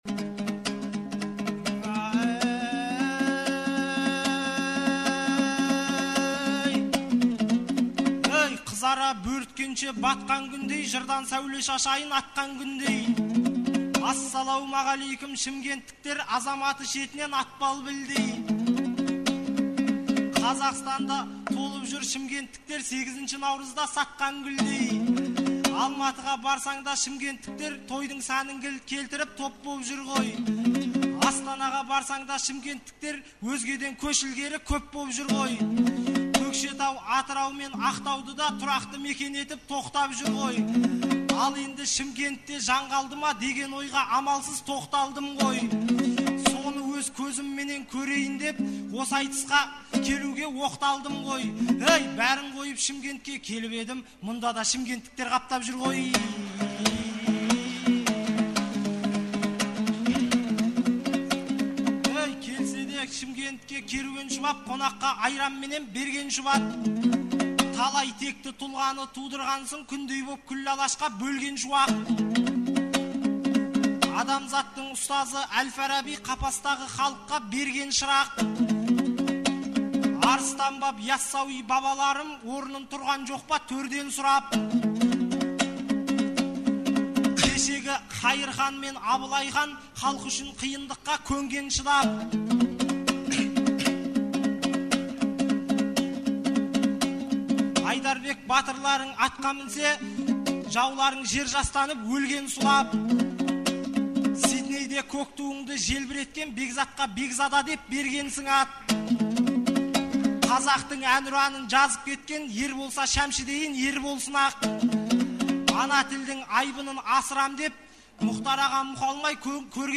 Наурыздың 15-16-сы күндері Шымкентте республикалық «Наурыз» айтысы өтті. 2004 жылдан бері тұрақты өтіп келе жатқан бұл айтыс биылғы жылы Төле бидің 350 және Абылай ханның 300 жылдықтарына арналды.